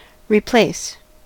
replace: Wikimedia Commons US English Pronunciations
En-us-replace.WAV